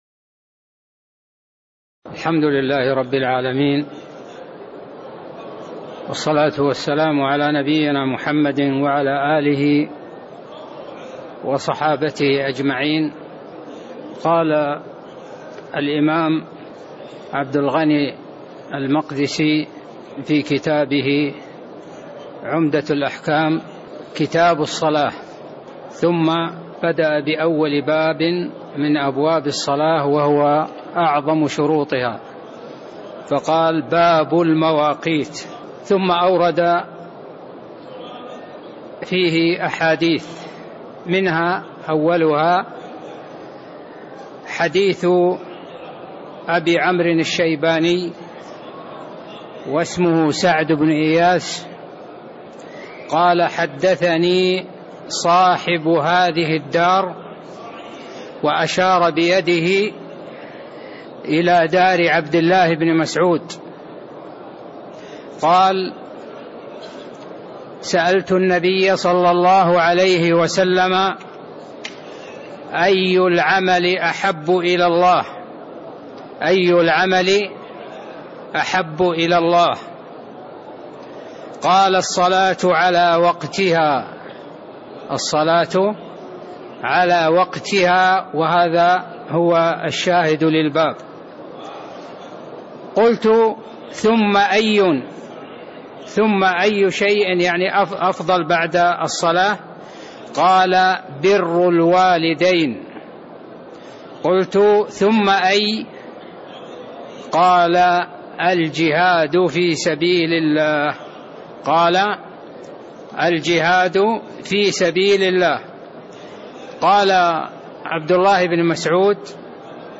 تاريخ النشر ٥ شعبان ١٤٣٥ هـ المكان: المسجد النبوي الشيخ